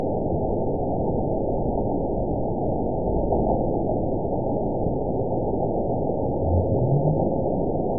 event 911274 date 02/20/22 time 15:34:35 GMT (3 years, 2 months ago) score 9.54 location TSS-AB02 detected by nrw target species NRW annotations +NRW Spectrogram: Frequency (kHz) vs. Time (s) audio not available .wav